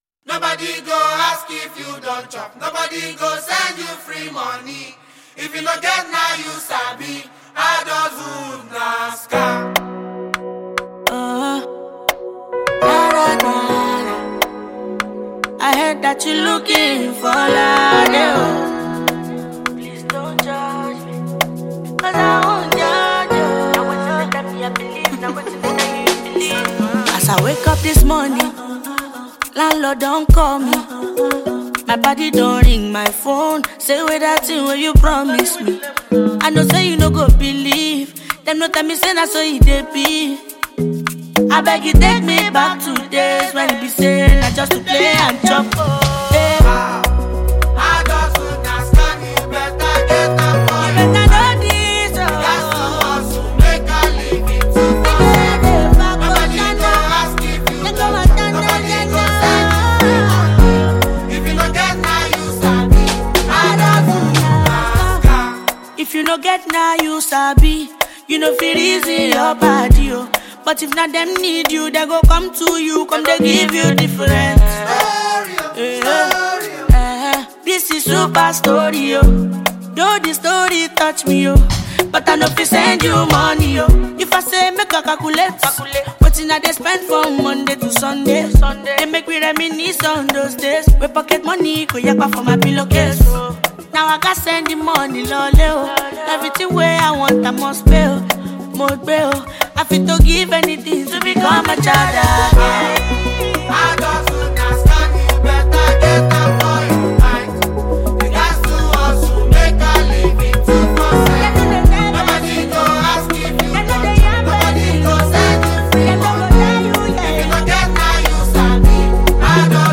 Nigerian gifted singer